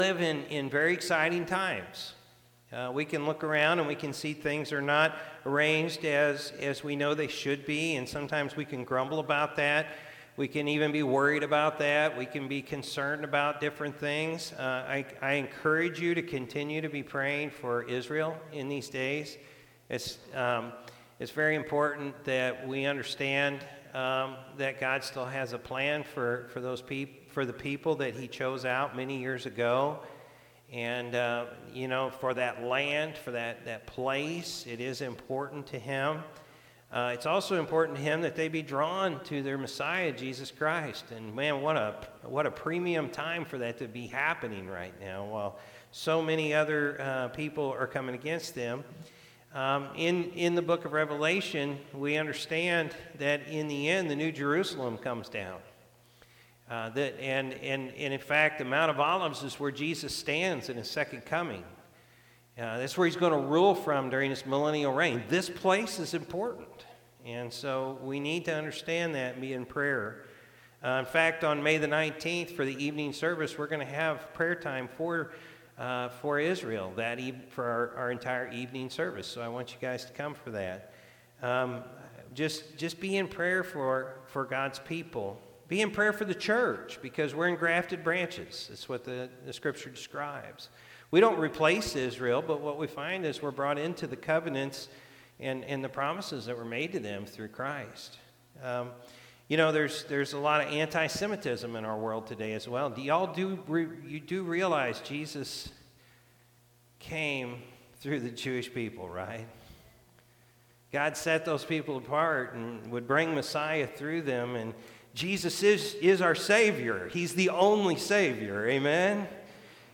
May-5-2024-Morning-Service.mp3